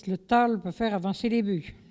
Il chante pour faire avancer les bœufs
Locution